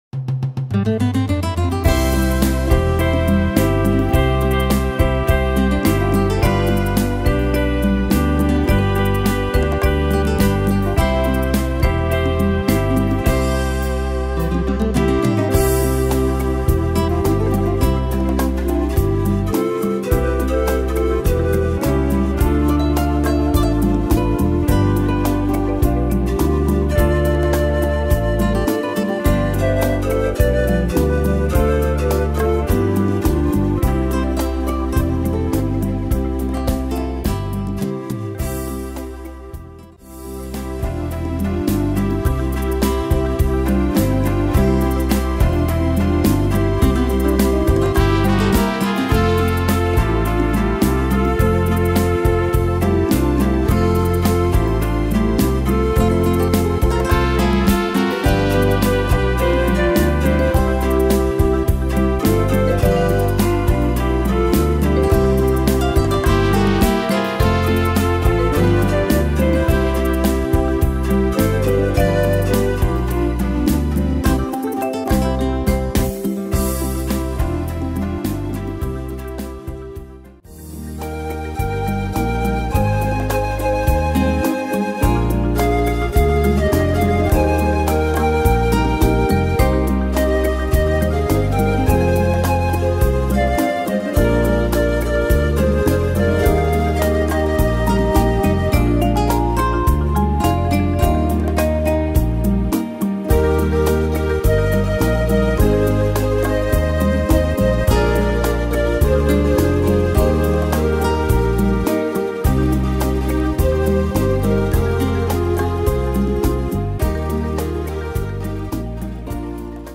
Tempo: 105 / Tonart: C-Dur